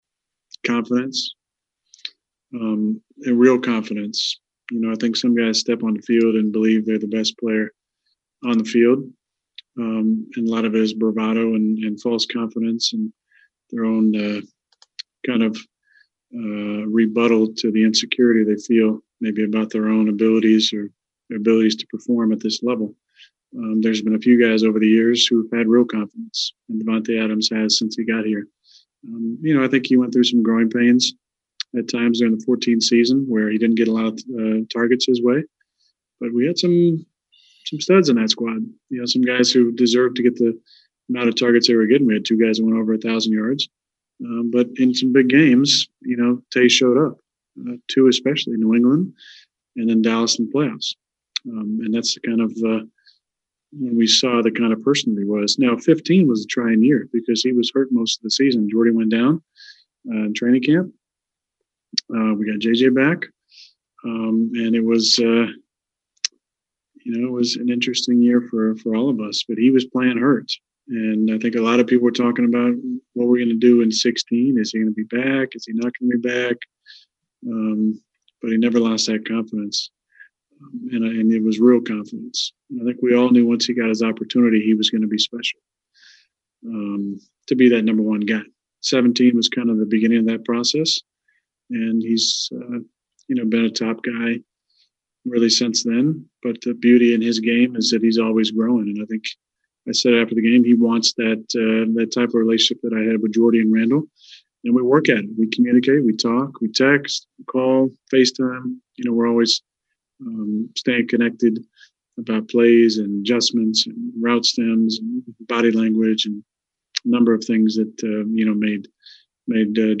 Both players met reporters today to talk about how their relationship has evolved first off the field, and then spectacularly, on the field in recent years.